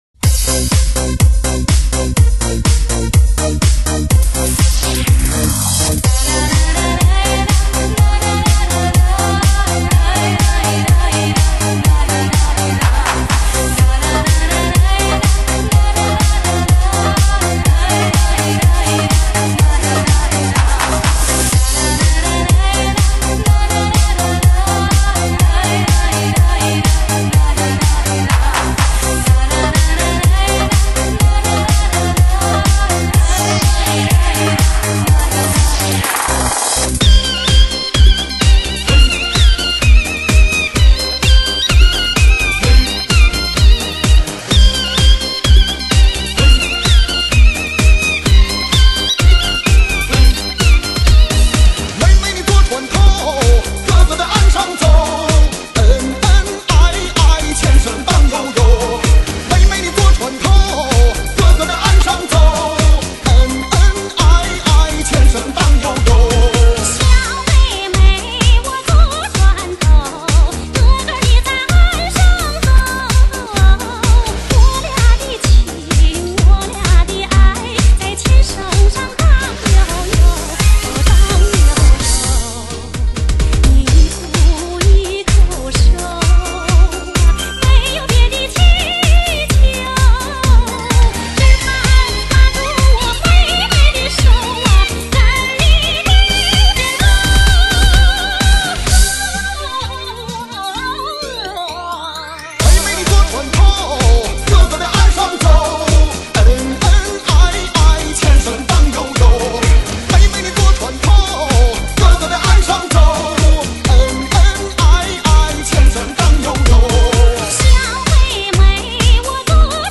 华语dj